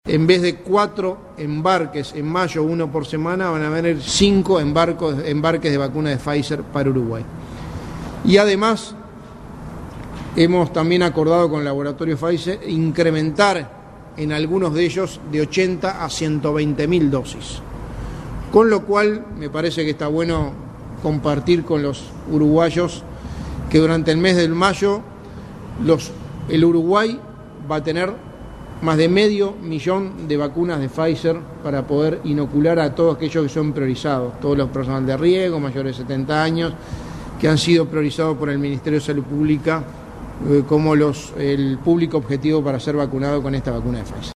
Por otra parte, Delgado informó en conferencia de prensa que el envío que se esperaba para este miércoles fue atrasado para el próximo domingo a las 00:50 horas.